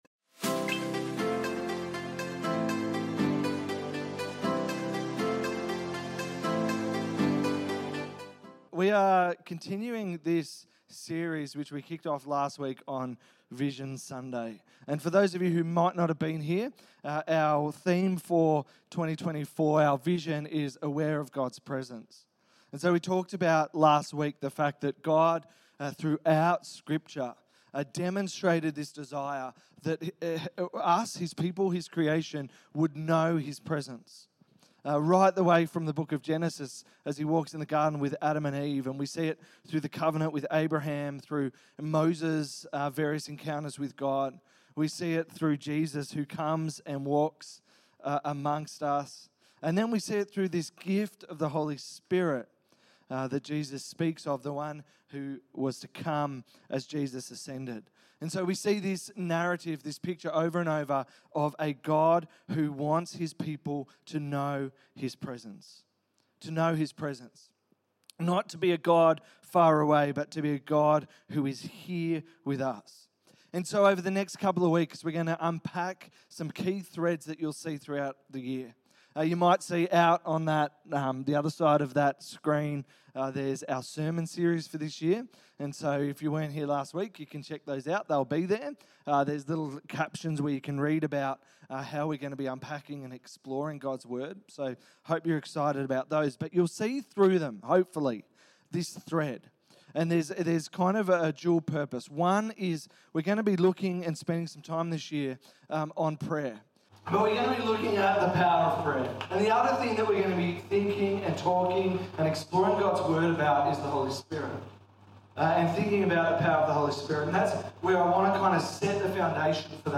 In this message we explore the way the Holy Spirit works in our lives to transform us into the likeness of Christ.